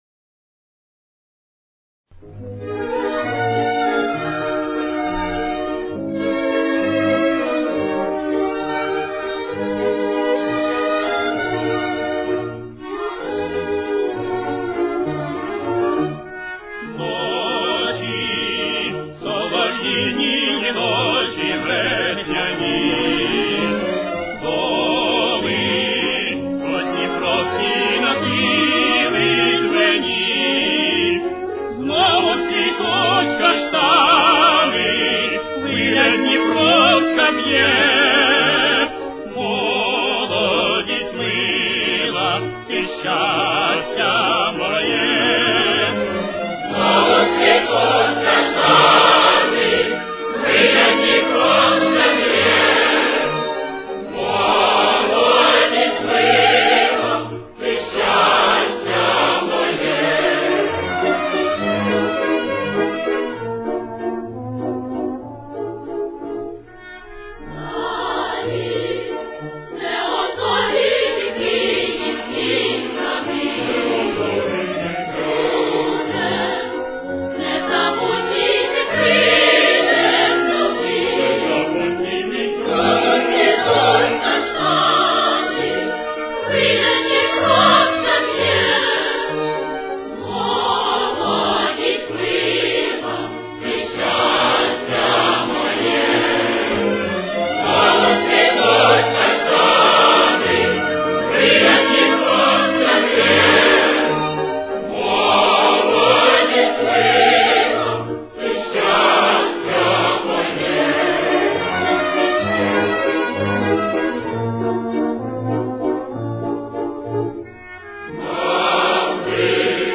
с очень низким качеством (16 – 32 кБит/с)
Темп: 195.